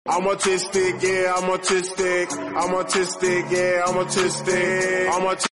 this dancing dinosaur with a song saying “i’m autistic”